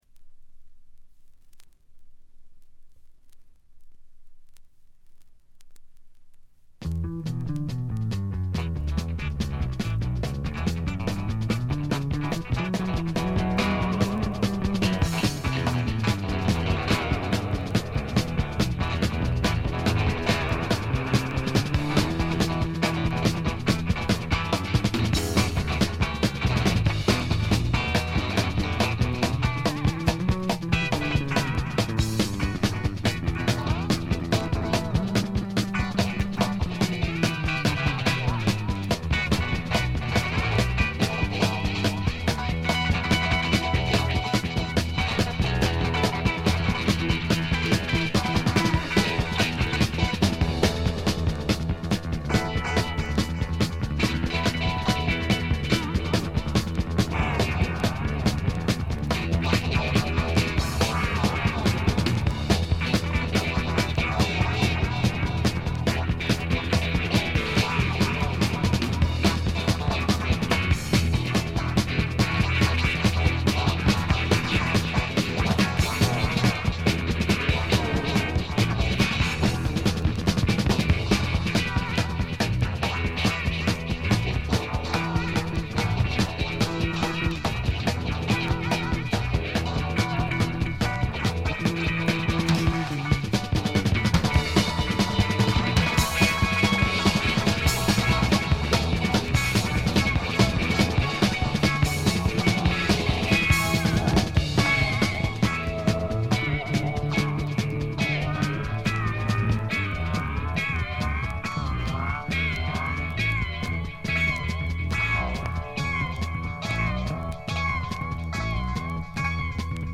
静音部で軽微なチリプチ。
試聴曲は現品からの取り込み音源です。